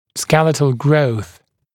[‘skelɪtl grəuθ][‘скелитл гроус]скелетный рост